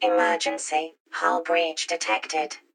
SeamothHullWarning.ogg